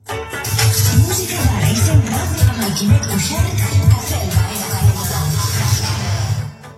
Indicatiu de la radiofórmula